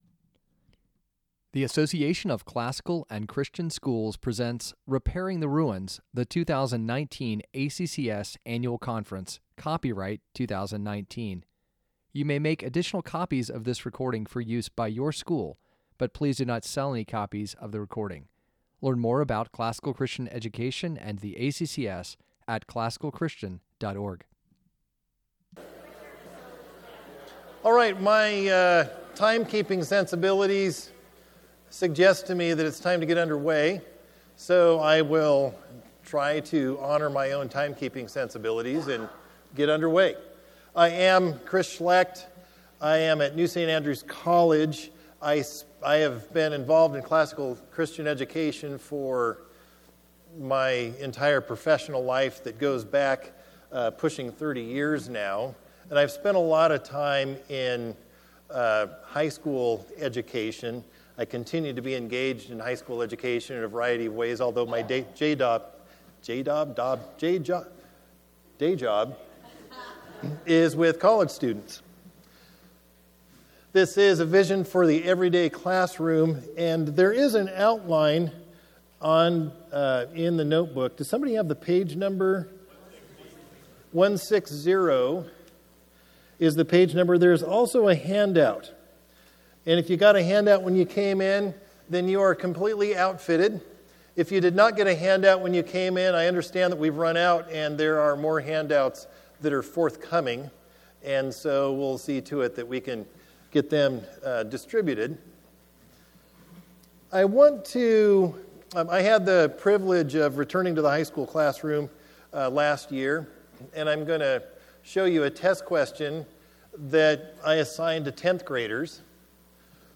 2019 Foundations Talk | 01:02:37 | 7-12, Teacher & Classroom, General Classroom